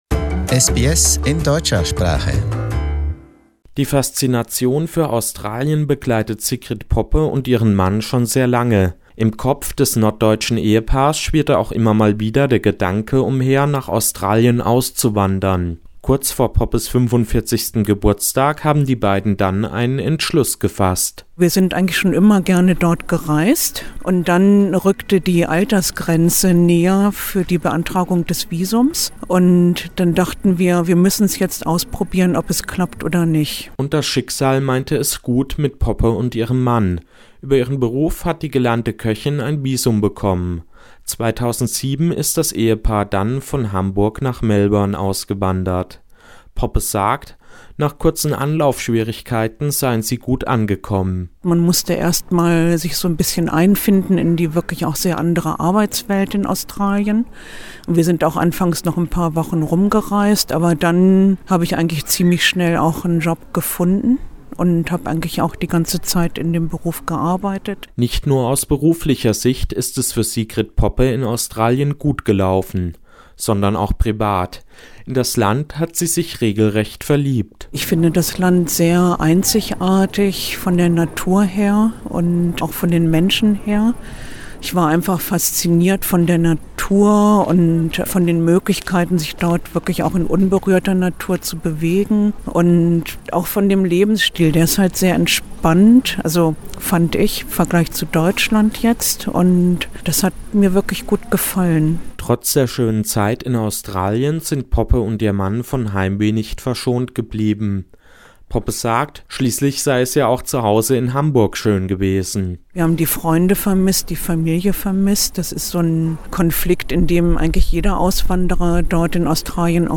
For more, listen to the interview with the passionate migrant who misses Australia and its people.